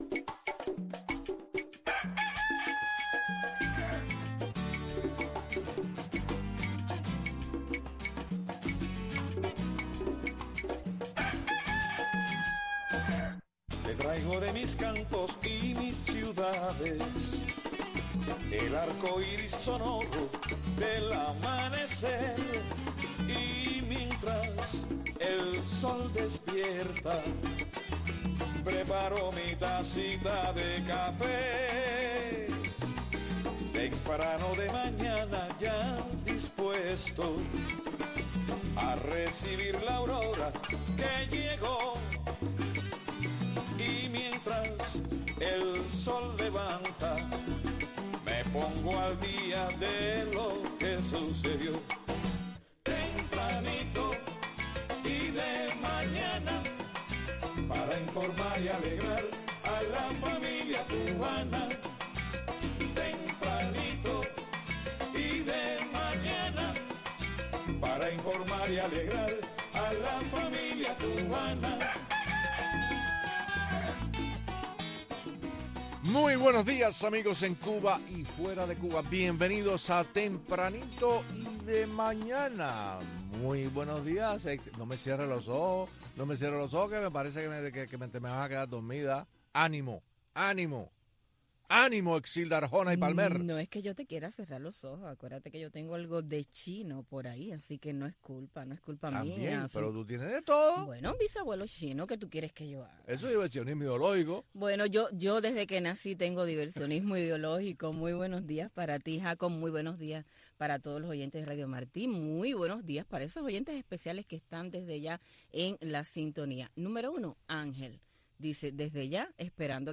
Disfrute el primer café de la mañana escuchando a Tempranito, una atinada combinación de noticiero y magazine, con los últimos acontecimientos que se producen en Cuba y el resto del mundo.